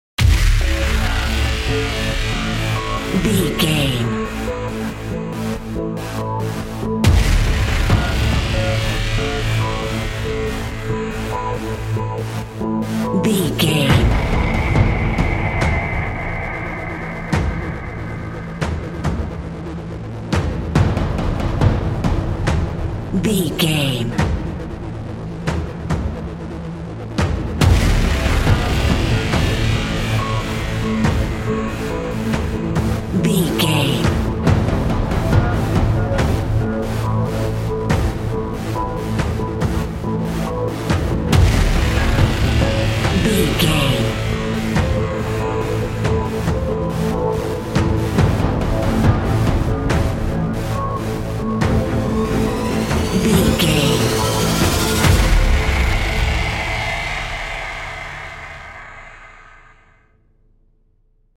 Aeolian/Minor
SEAMLESS LOOPING?
DOES THIS CLIP CONTAINS LYRICS OR HUMAN VOICE?
WHAT’S THE TEMPO OF THE CLIP?
Fast
synthesiser
percussion